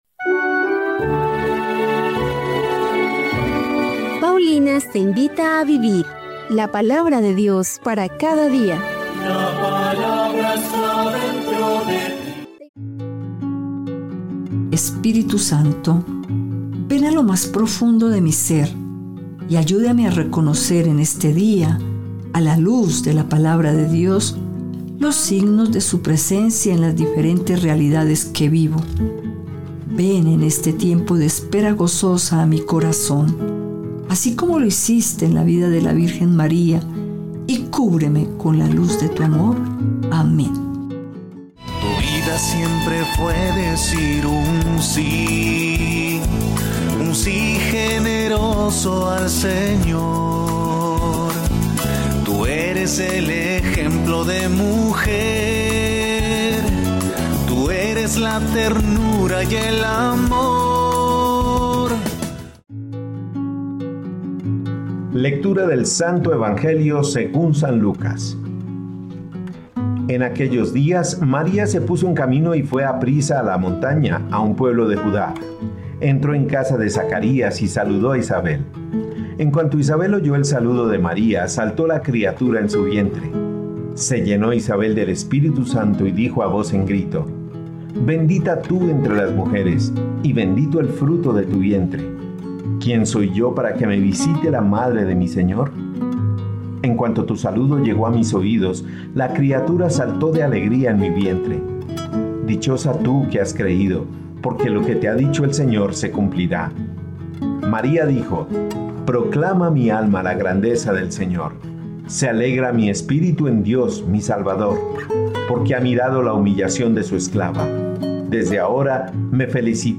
Liturgia diaria